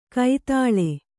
♪ kai tāḷe